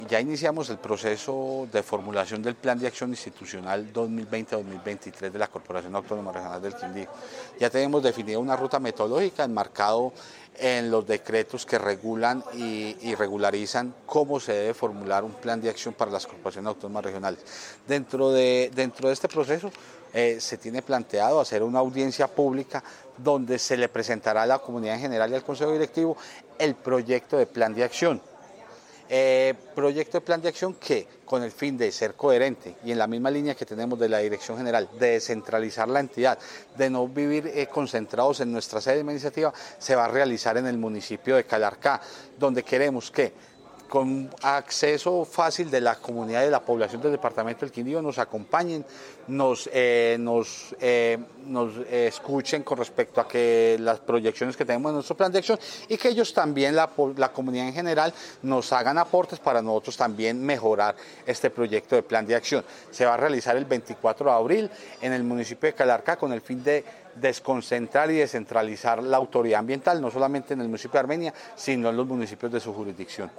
AUDIO DIRECTOR DE LA CORPORACIÓN AUTÓNOMA REGIONAL DEL QUINDÍO – JOSÉ MANUEL CORTÉS OROZCO:
AUDIO_DIRECTOR_DE_LA_CRQ_NOTA_PLAN_DE_ACCIÓN.mp3